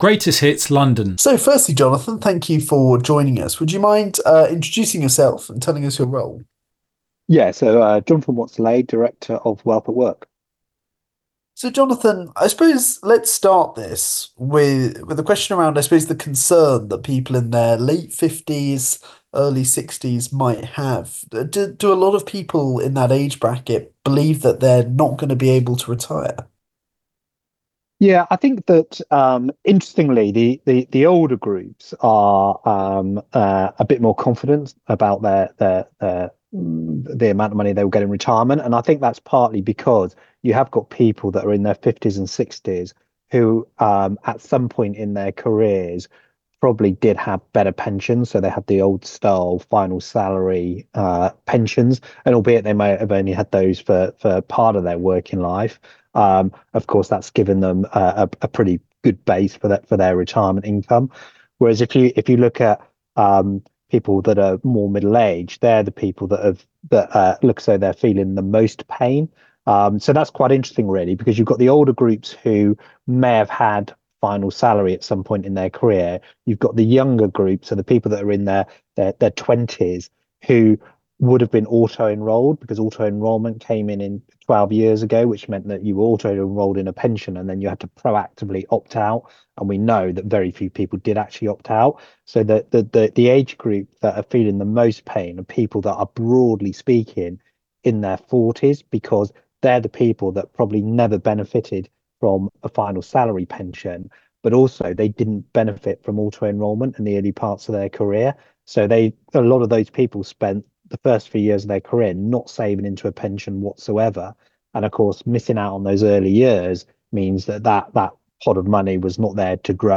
Interview with Greatest Hits Radio.